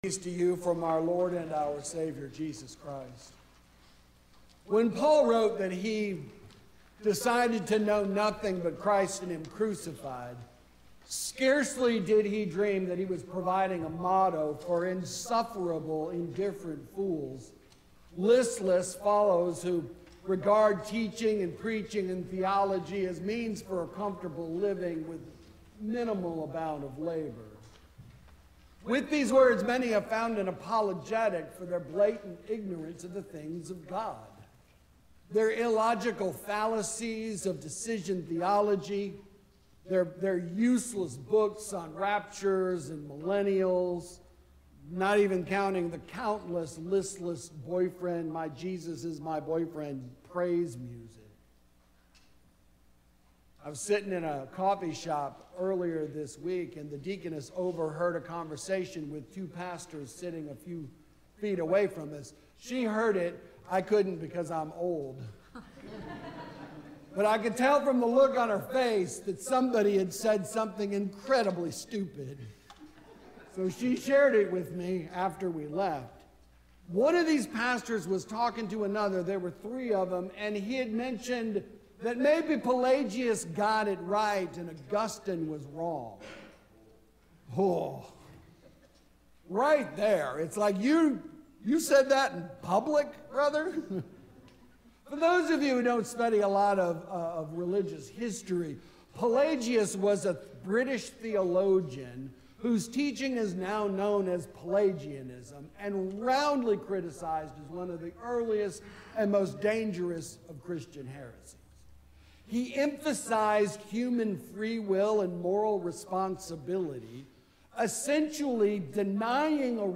Sermon for the Fifth Sunday after The Epiphany